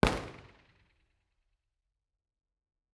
IR_EigenmikeHHL1_processed.wav